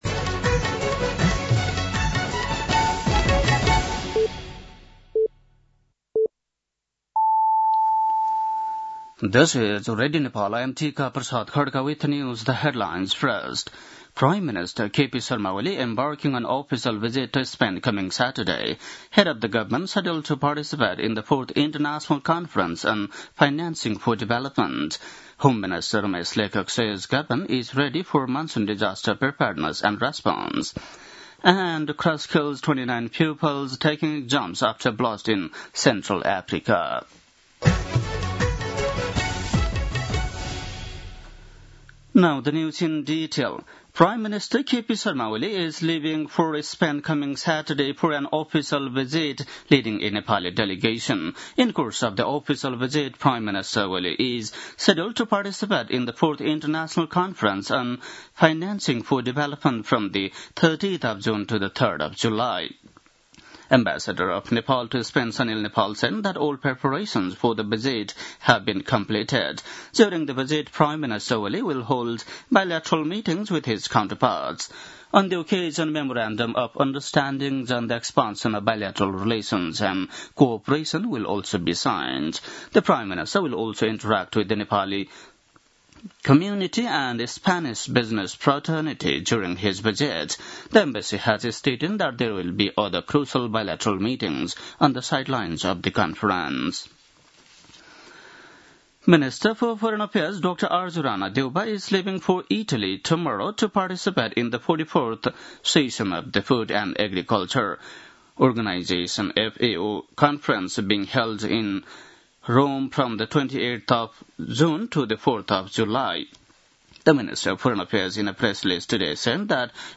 An online outlet of Nepal's national radio broadcaster
बेलुकी ८ बजेको अङ्ग्रेजी समाचार : १२ असार , २०८२
8-pm-nepali-news-.mp3